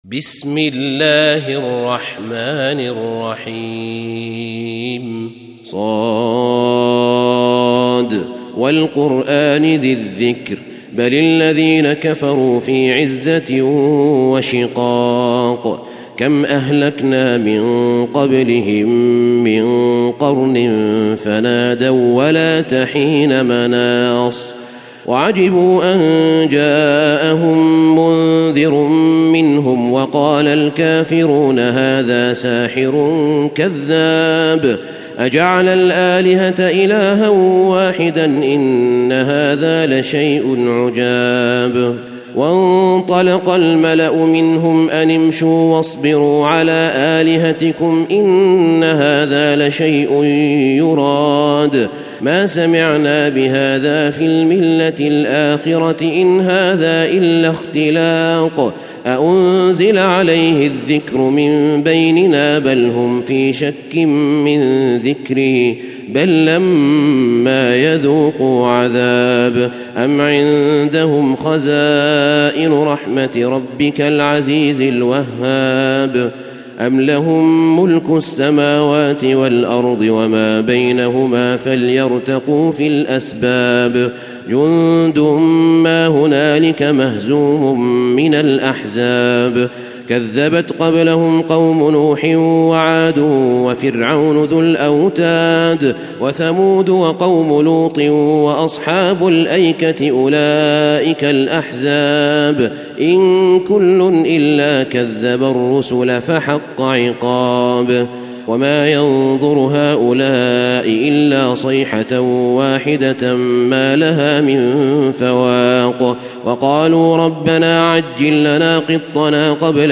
تلاوة سورة ص
تاريخ النشر ١ محرم ١٤٣٤ هـ المكان: المسجد النبوي الشيخ: فضيلة الشيخ أحمد بن طالب بن حميد فضيلة الشيخ أحمد بن طالب بن حميد سورة ص